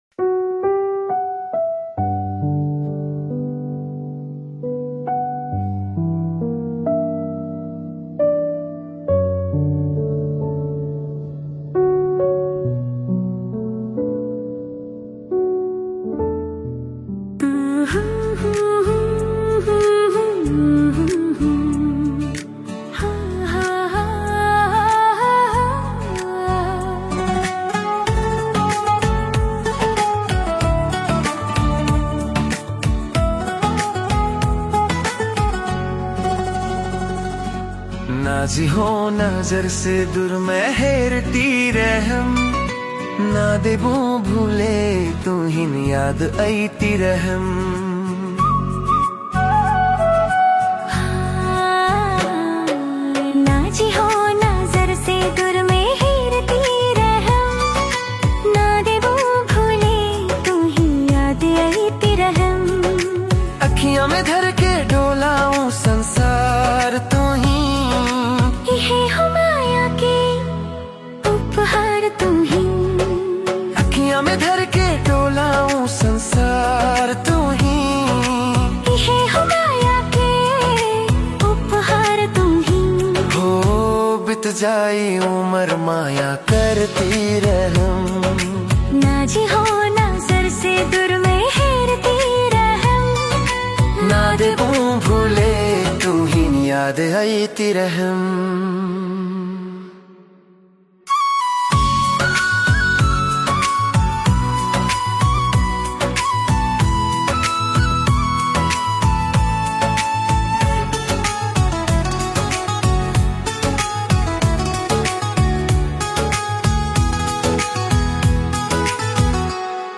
Ai Music Tharu Romantic Song